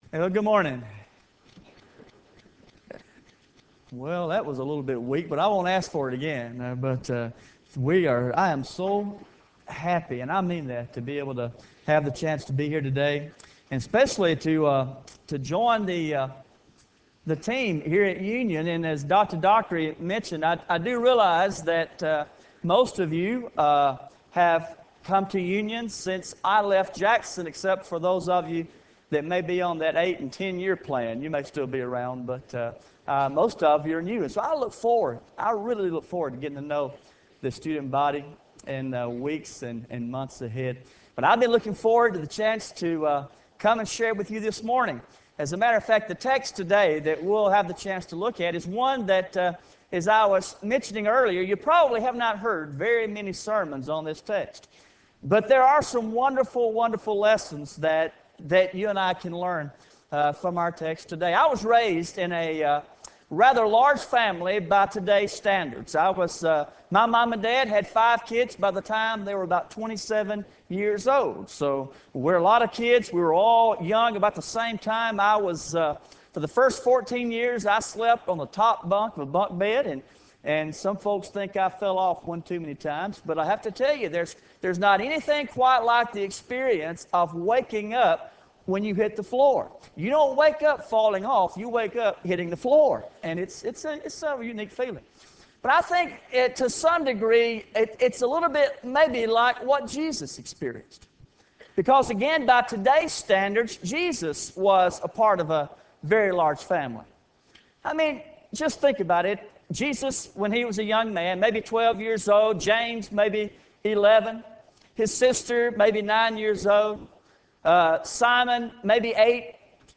Chapel